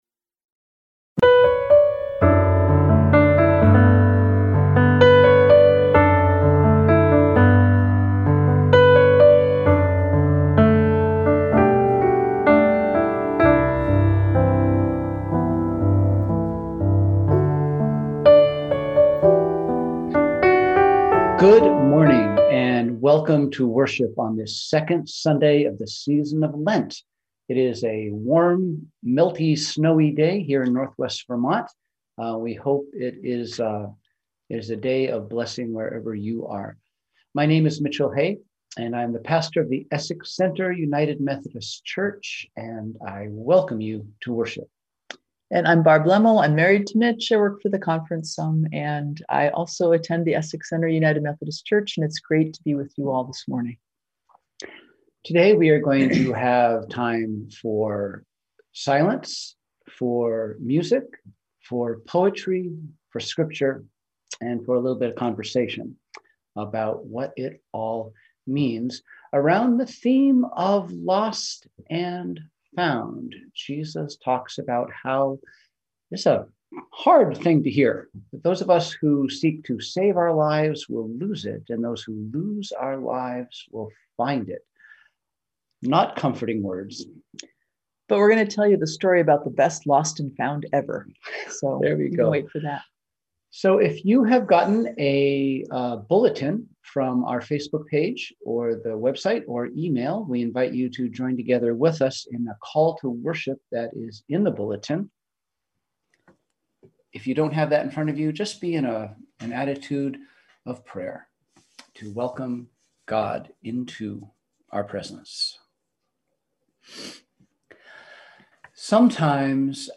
We held virtual worship on Sunday, February 28, 2021 at 10AM!